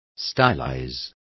Also find out how estilicen is pronounced correctly.